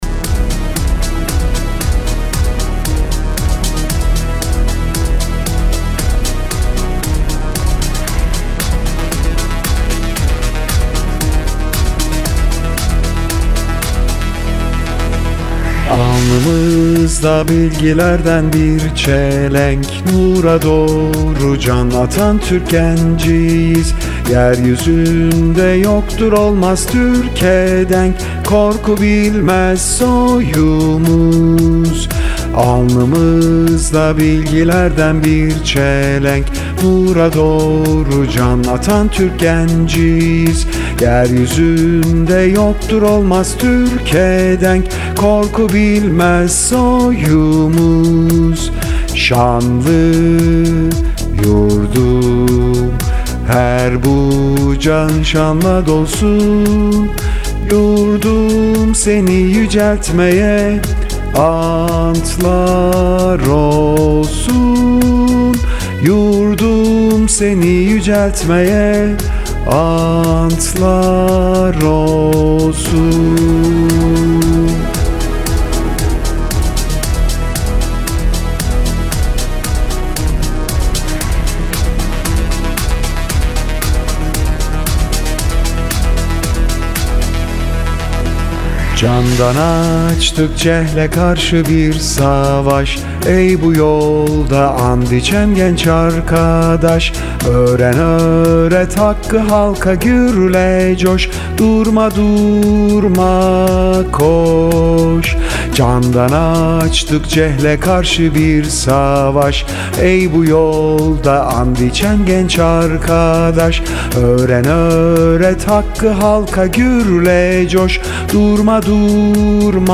REMİX SÖZLÜ SES KAYDI E MAJOR